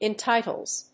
/ɛˈntaɪtʌlz(米国英語), eˈntaɪtʌlz(英国英語)/